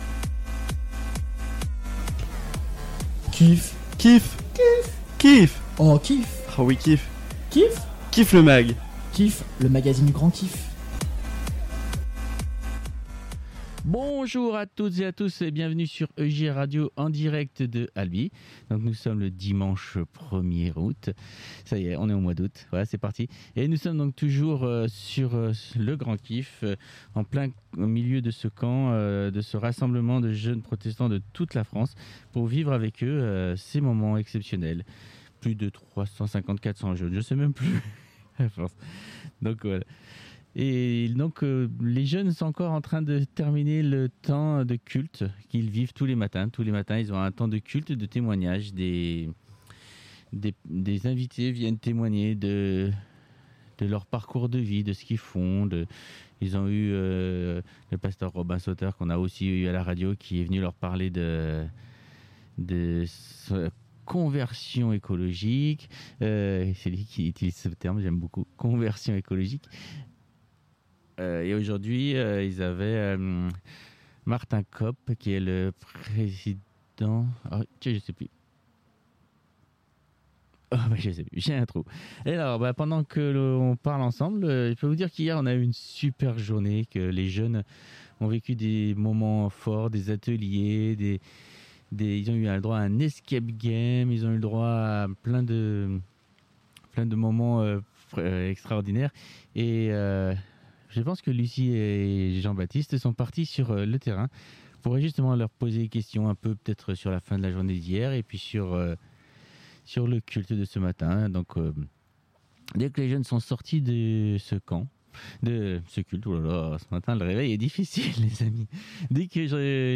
Le 6ème numéro du KIFFMAG en direct de ALBI le 01/08/2021 à 10h30